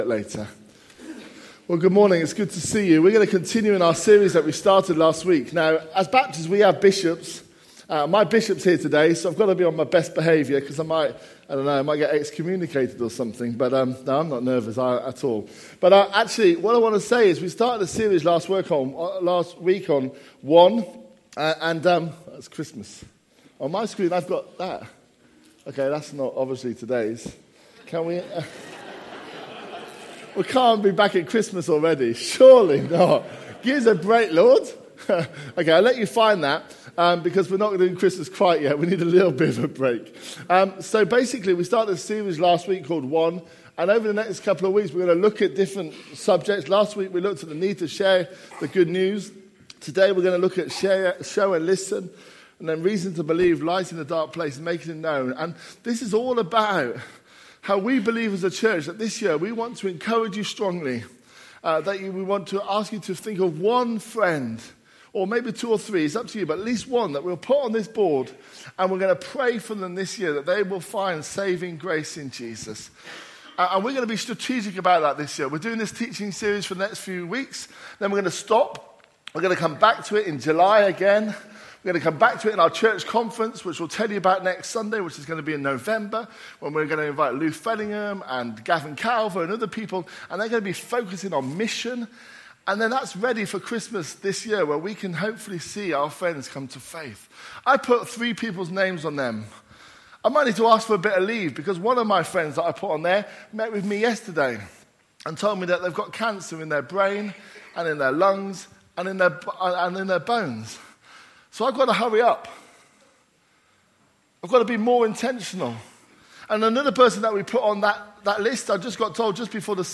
A message from the series "One."